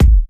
Kick_05.wav